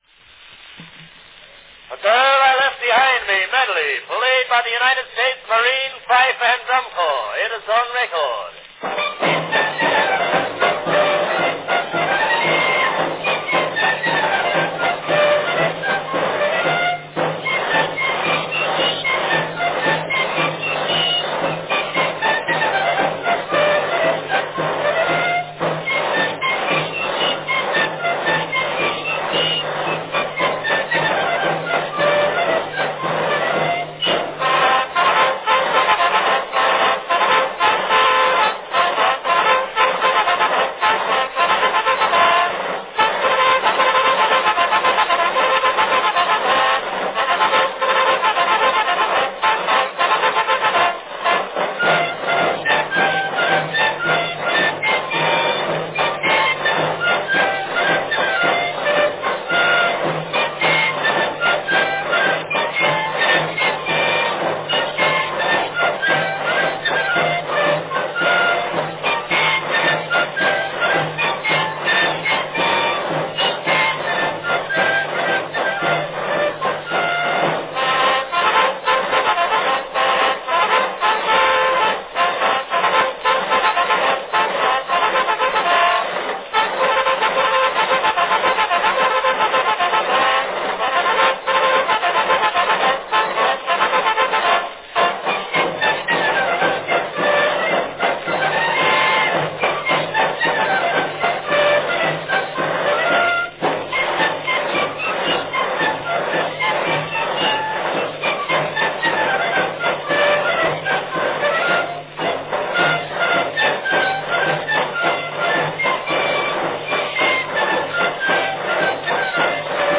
From 1903, an early field recording – the United States Marine Fife and Drum Corps plays The Girl I Left Behind Me Medley.
Category Fife & drum
Performed by U. S. Marine Fife and Drum Corps
A wonderful recording made outdoors rather than in Edison's music room.
It is played by the U. S. Marine Fife and Drum Corps, with good work by the drums, fifes and bugles.